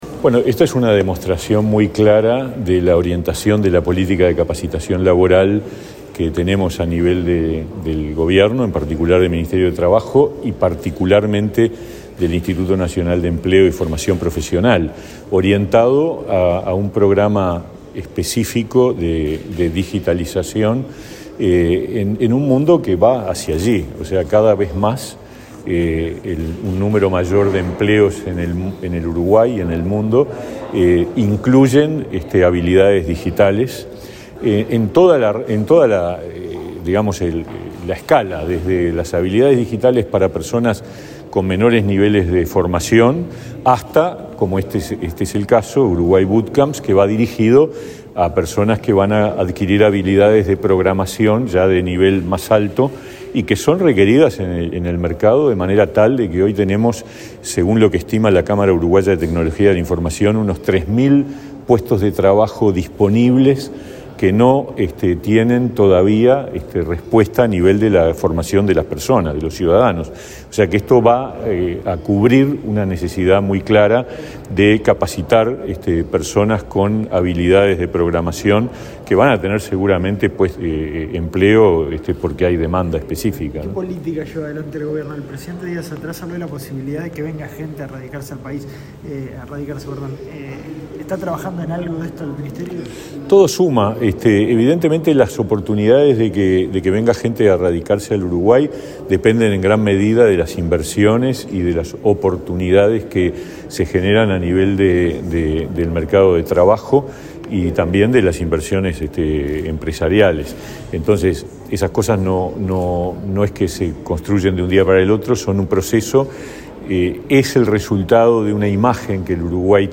Declaraciones del ministro de Trabajo, Pablo Mieres